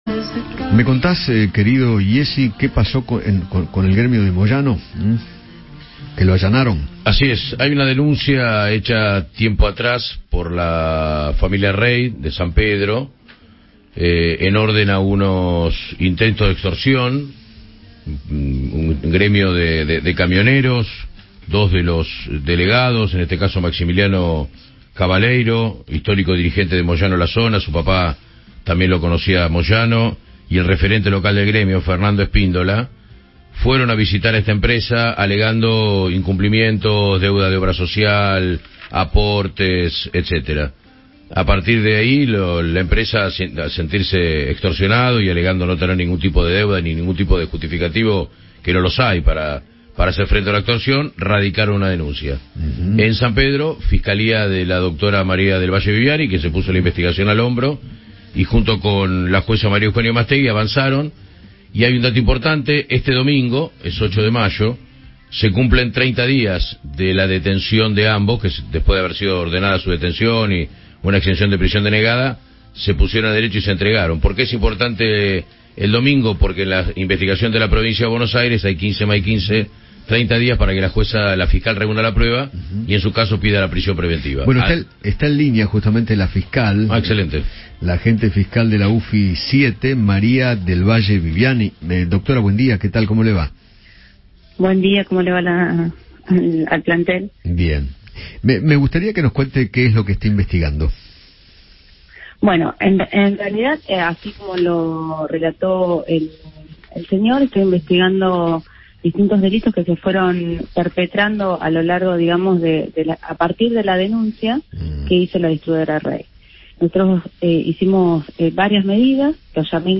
María Del Valle Viviani , fiscal de la UFI 7, conversó con Eduardo Feinmann sobre el allanamiento a la sede nacional del Sindicato de Camioneros en el marco de la causa por el bloqueo a una empresa de San Pedro.